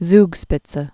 (zgspĭt-sə, tskshpĭt-)